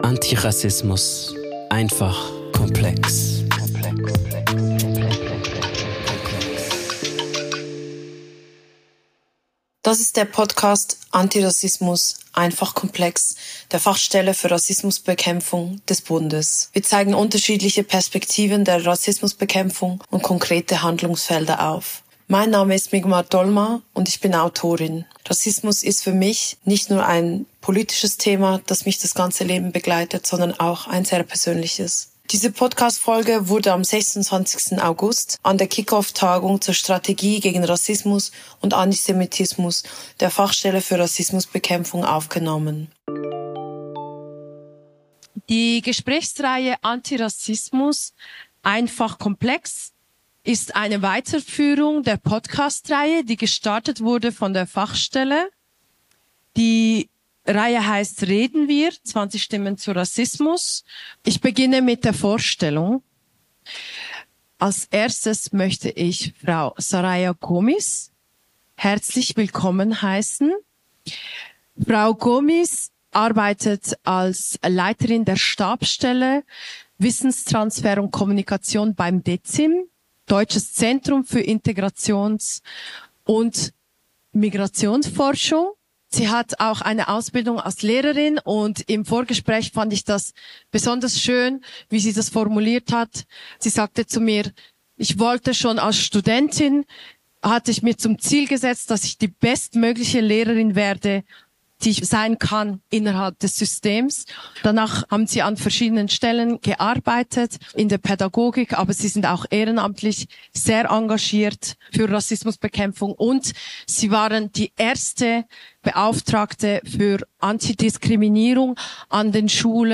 Beschreibung vor 4 Monaten Dieser Podcast wurde an der Kick-Off-Tagung zur Strategie gegen Rassismus und Antisemitismus der Fachstelle für Rassismusbekämpfung am 26. August 2025 aufgezeichnet.
Ein Gespräch darüber, wie Behörden, Wissenschaft, Zivilgesellschaft und Politik gemeinsam Veränderung gestalten können.